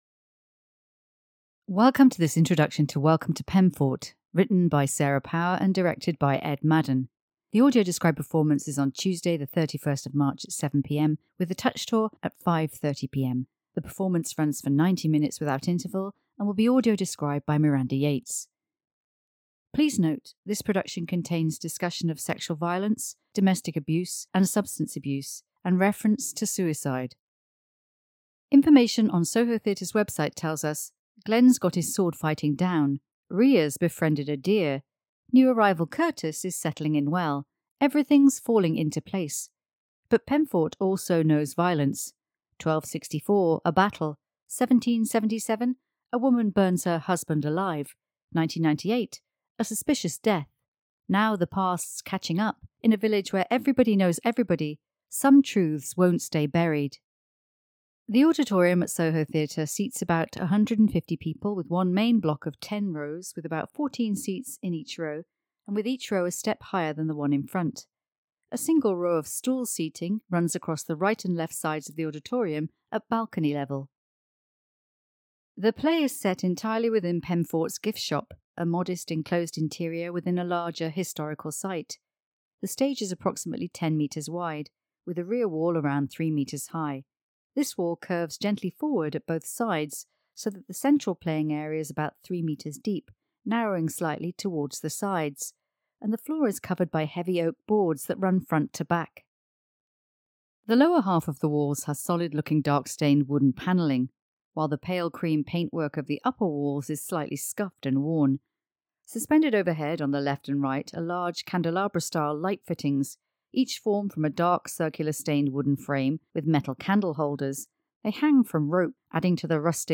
An audio description of the venue, stage and set, characters and costumes, as they appear for the performance.
WELCOME-TO-PEMFORT-Audio-Introduction.mp3